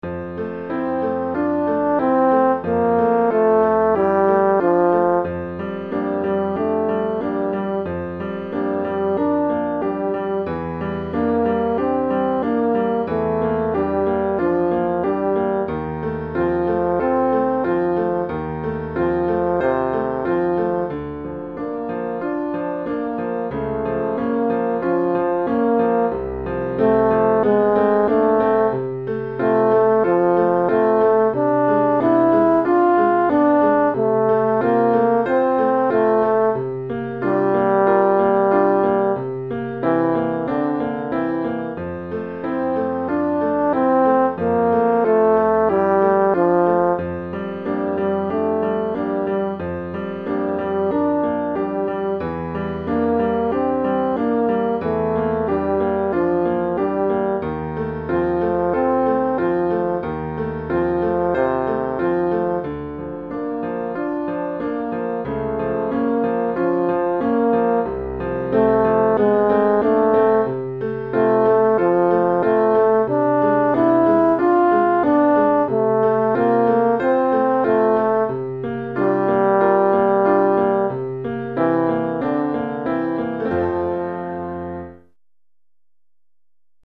Cor en Fa et Piano